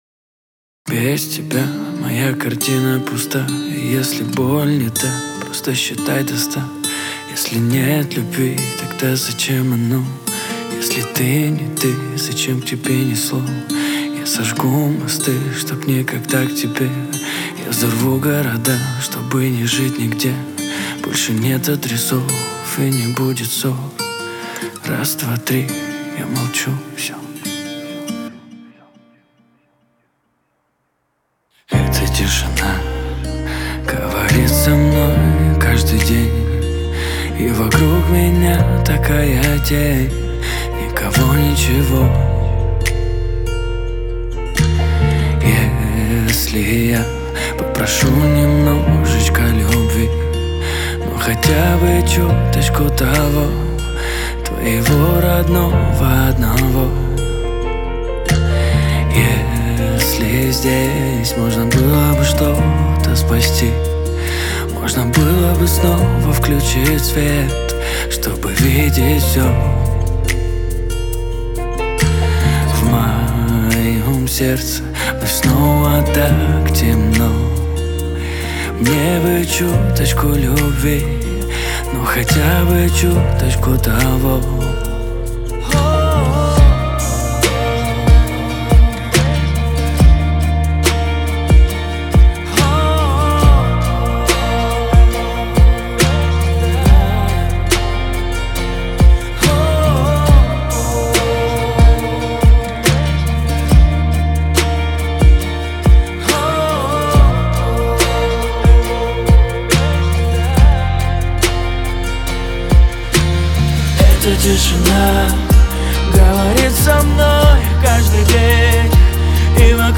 наполненная глубокими эмоциями и меланхолией.